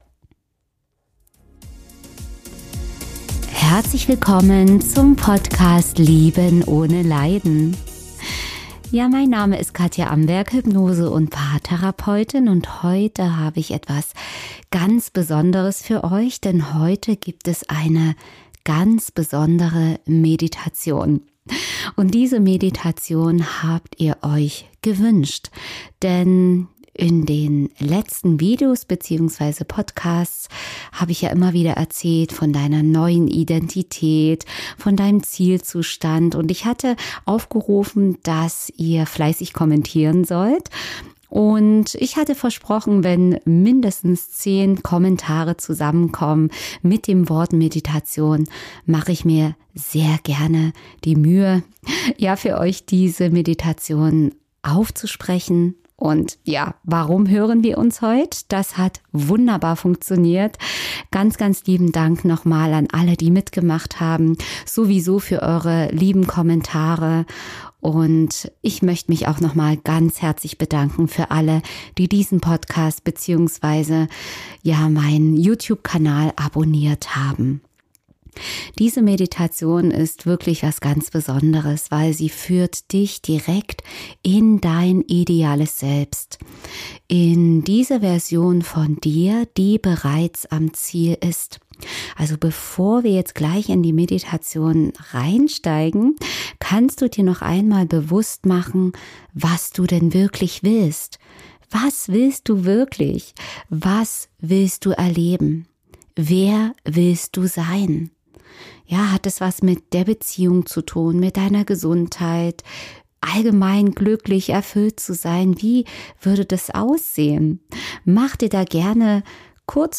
In dieser Meditation begibst du dich auf eine Reise zu deinem idealen Selbst.